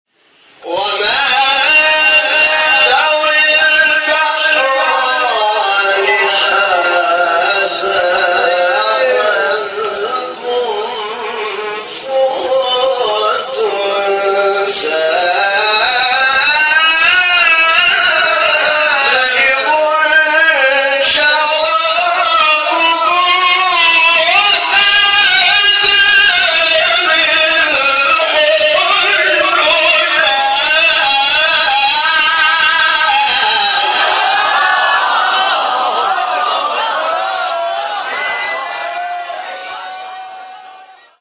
آیه 12 فاطر استاد حامد شاکرنژاد مقام حجاز | نغمات قرآن | دانلود تلاوت قرآن